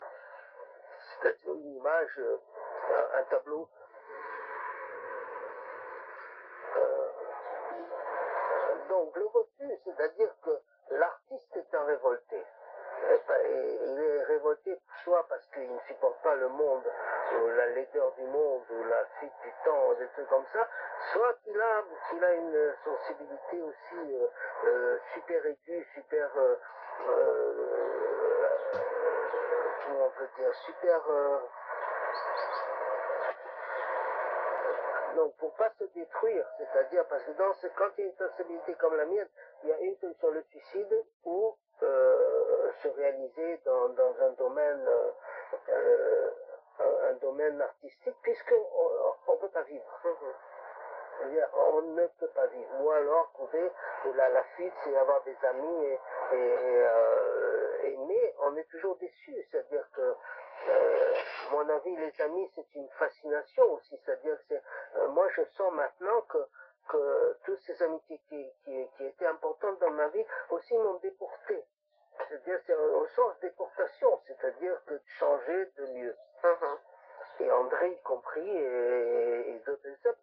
Can this background traffic noise be filtered out?
For example the car hooters can be made less obvious, some of the traffic noise reduced, the clinking of coffee or tea cups and even that ringing telephone can be made less obvious.
hi, I have an old recording of a talk with background noises. Sounds to me like traffic but maybe more.